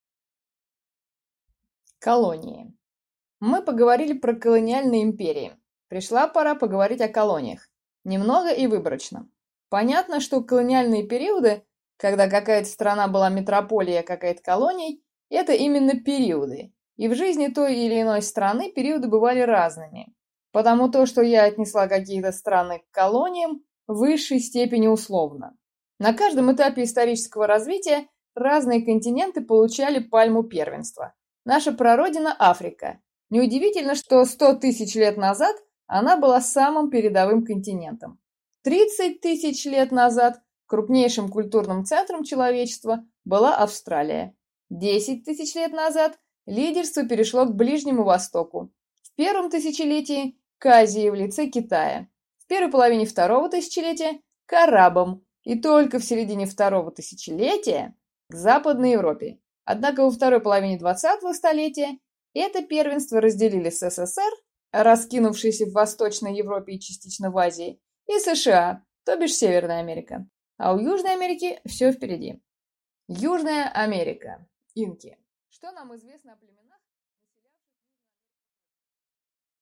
Аудиокнига Южная Америка. Инки | Библиотека аудиокниг